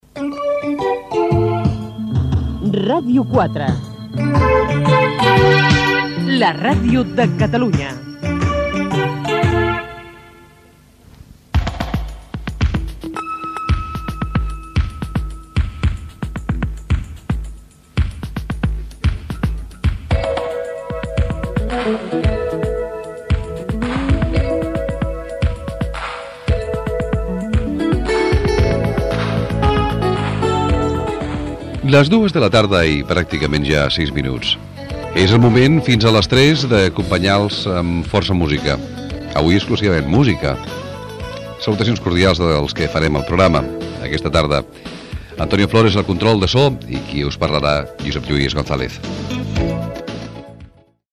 Indicatiu de la ràdio, hora, presentació del programa i equip
Entreteniment
FM